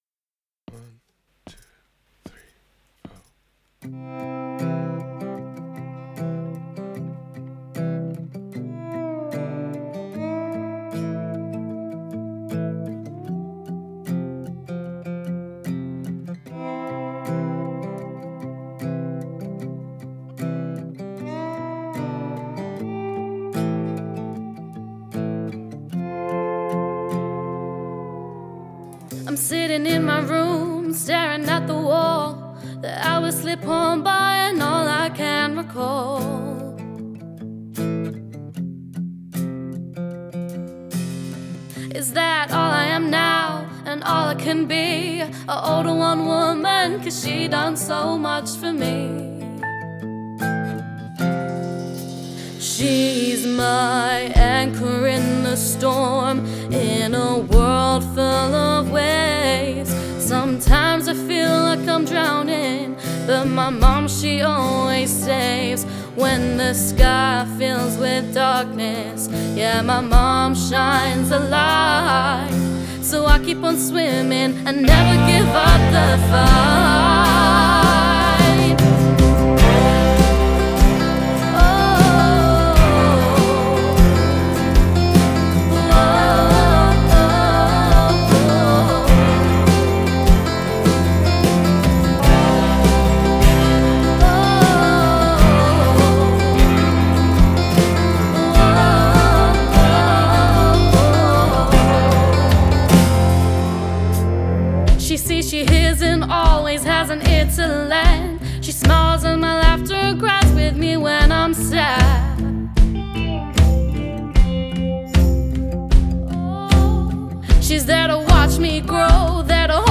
Country song that hit Top 1 in iTunes UK Country chart.
Composed, recorded, mixed and mastered completely by me.